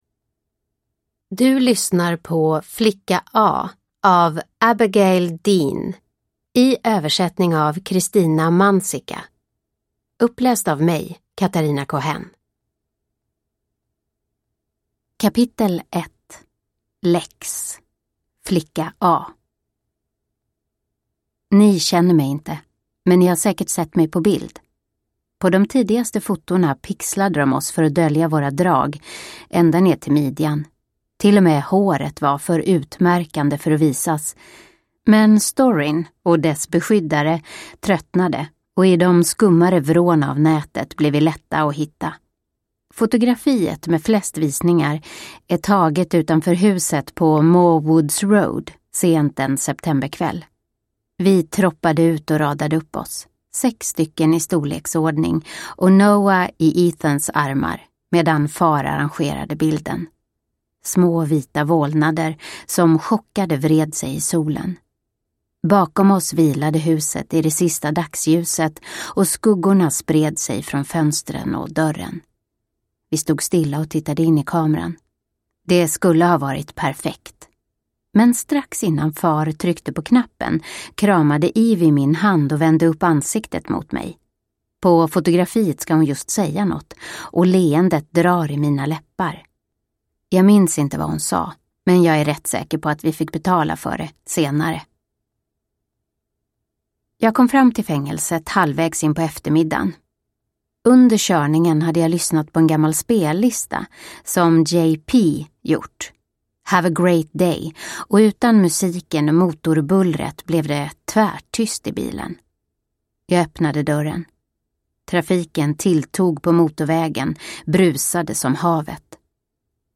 Flicka A – Ljudbok – Laddas ner